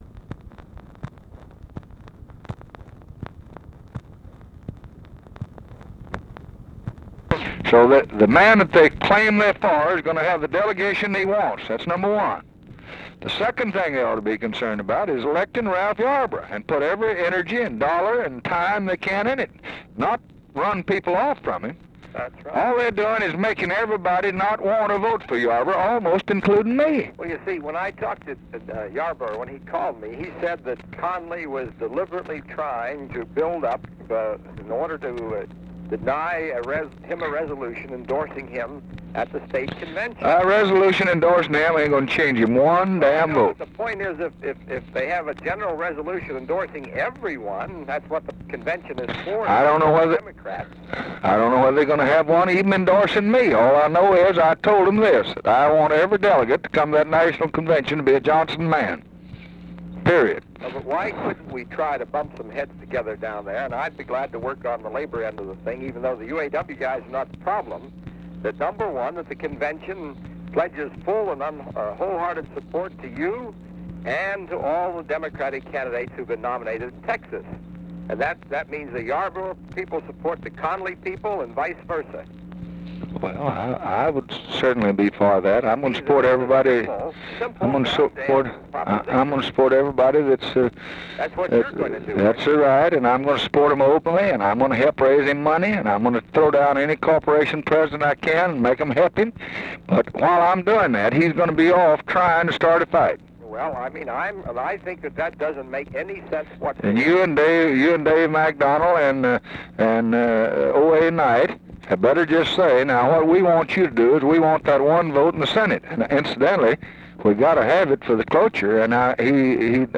Conversation with WALTER REUTHER, June 5, 1964
Secret White House Tapes